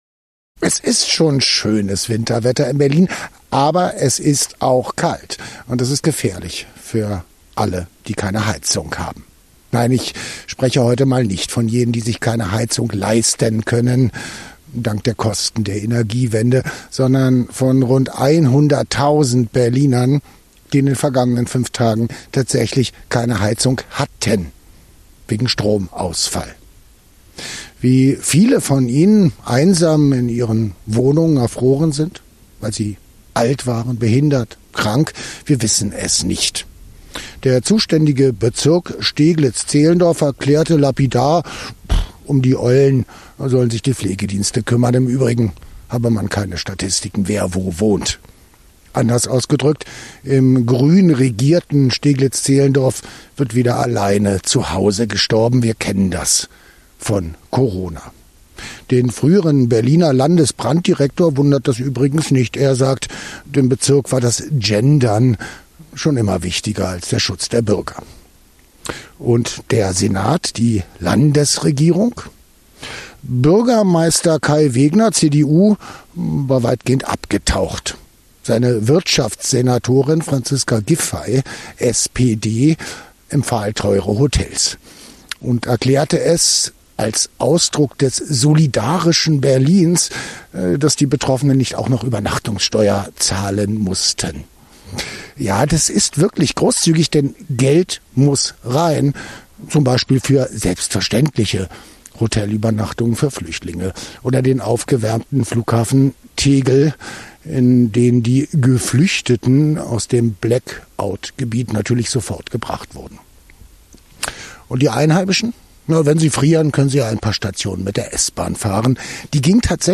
Kommentar: Arrogante Politiker lassen die Bürger erfrieren